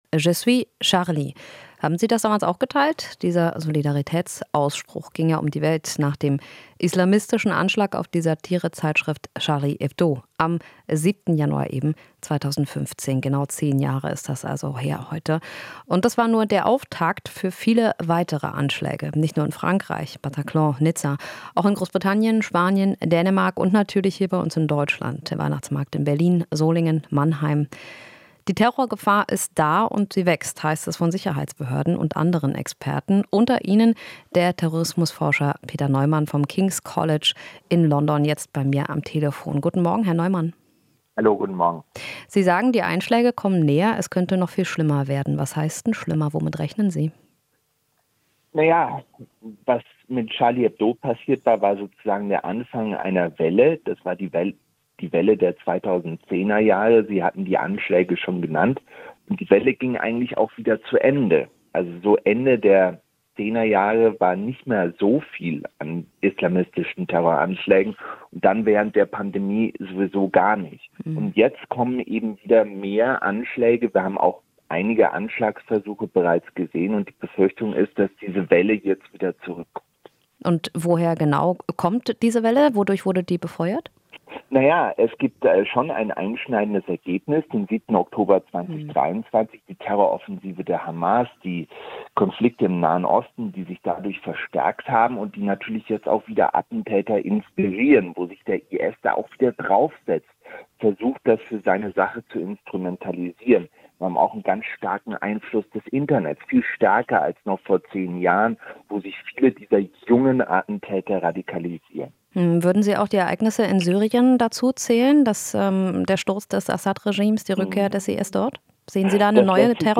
Interview - Terrorismusexperte: Anschlag auf "Charlie Hebdo" war Anfang einer Welle